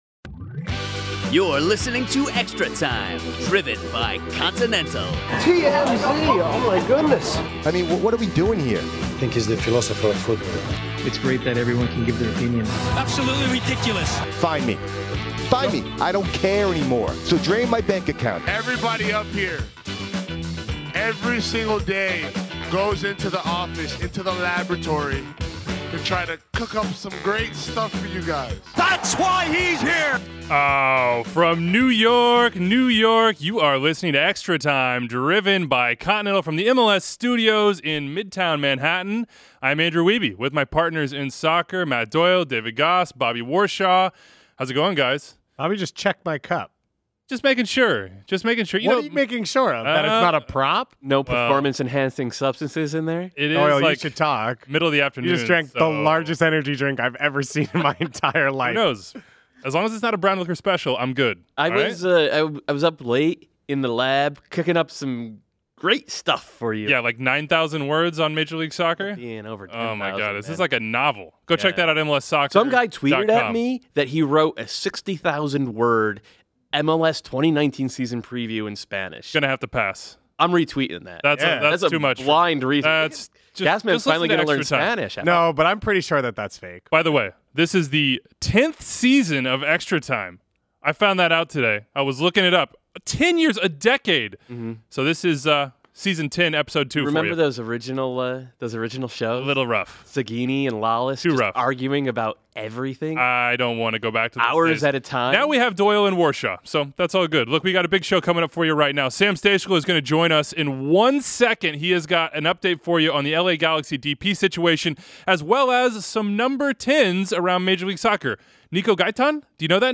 Also in this episode, an interview with Jordan Morris (35:11, Seattle Sounders FC) and more fearless listener predictions in the Mailbag.